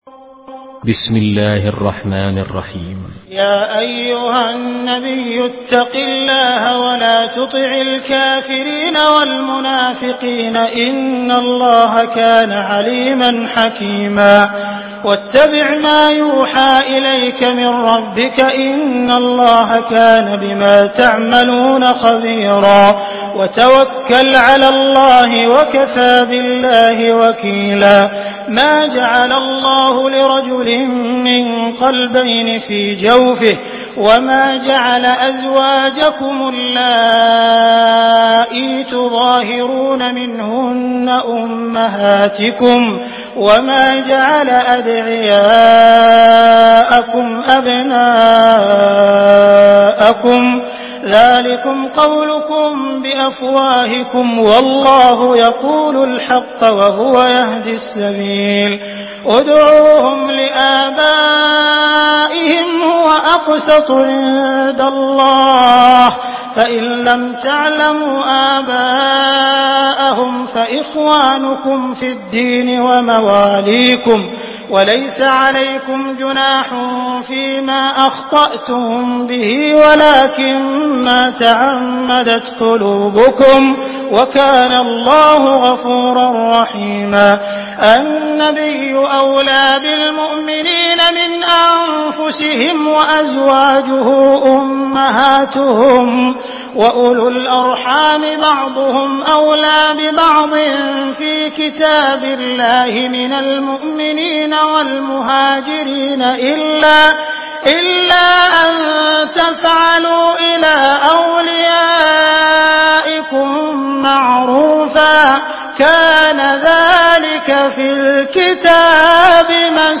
Surah Al-Ahzab MP3 in the Voice of Abdul Rahman Al Sudais in Hafs Narration
Surah Al-Ahzab MP3 by Abdul Rahman Al Sudais in Hafs An Asim narration.
Murattal